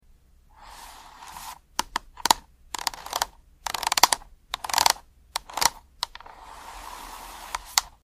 A close up of a finger sound effects free download
The ASMR sound duet is the nostalgic, slightly creaky sound of the old globe spinning, combined with the faint, airy "whoosh" of the clouds being generated.